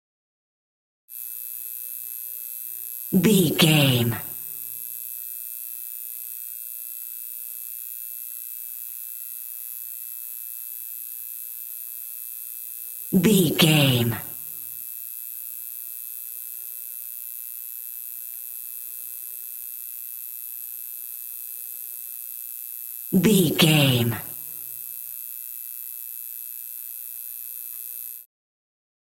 Neon light buzz
Sound Effects
urban